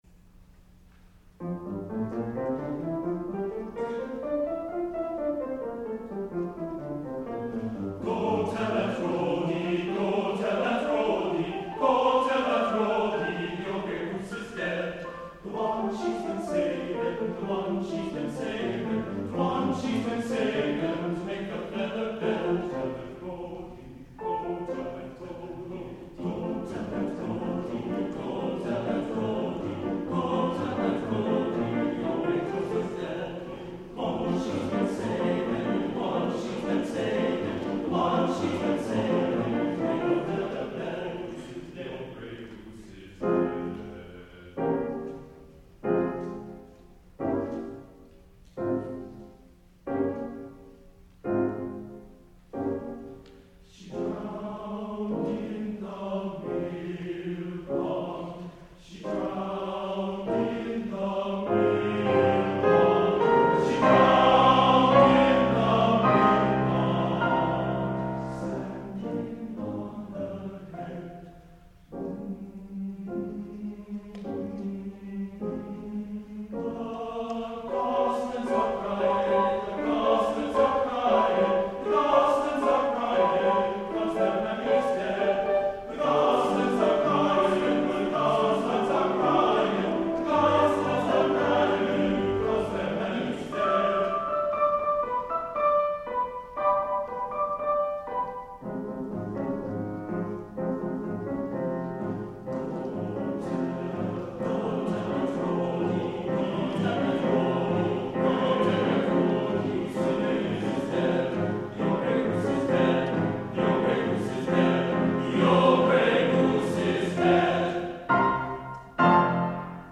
TTBB (4 voices men).
Type of Choir: TTBB (4 men voices ) Instrumentation: Piano